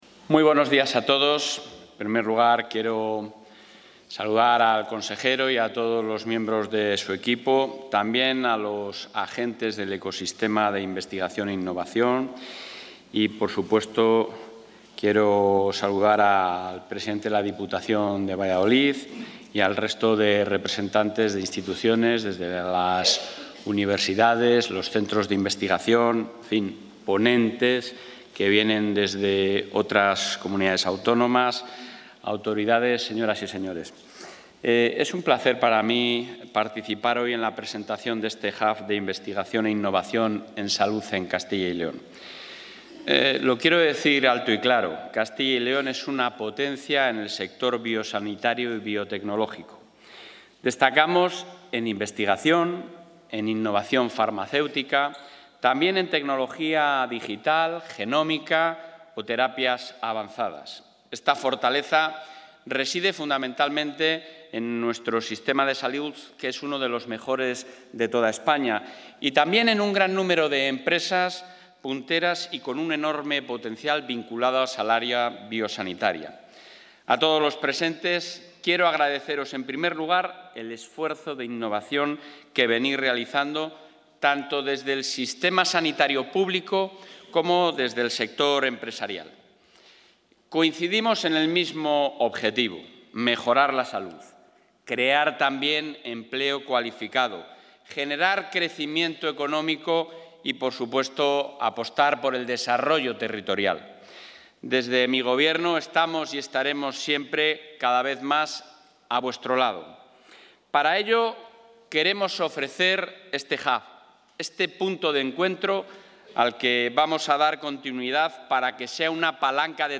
Intervención del presidente.